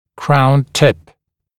[kraun tɪp][краун тип]верхушка коронки